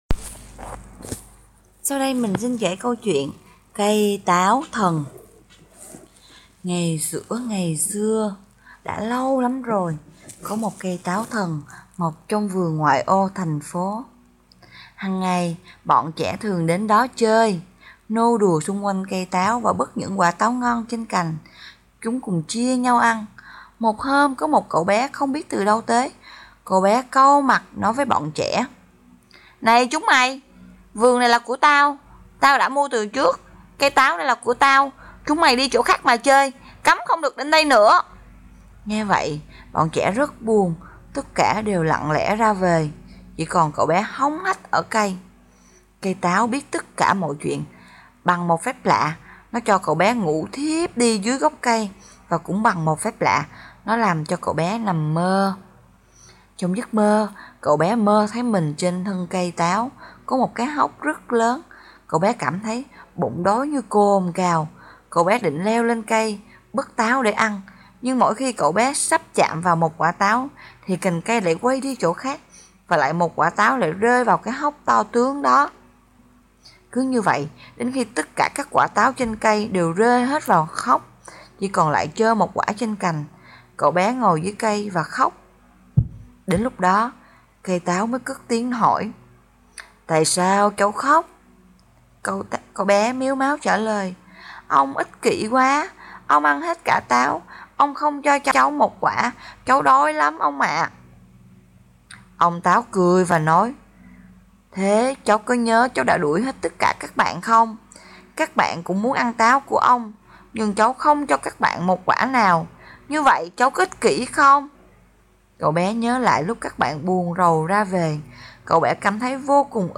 Sách nói | Cây táo thần